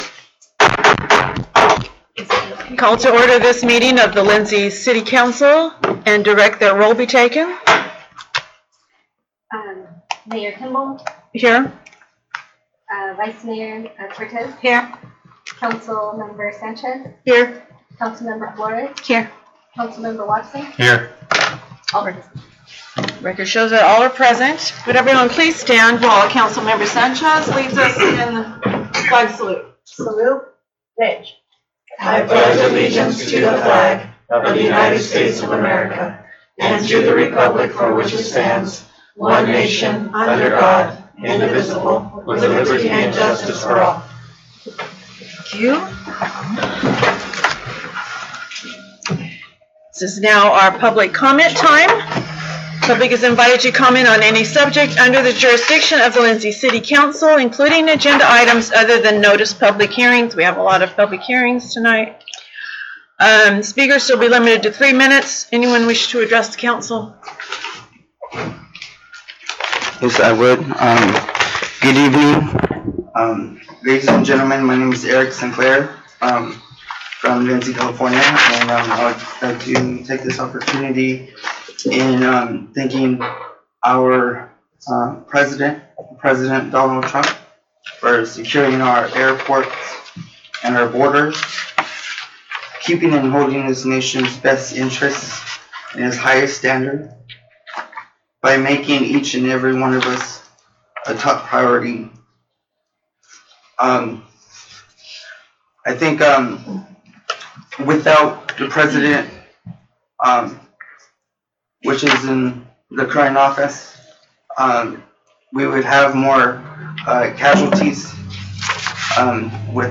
City Council Regular Meeting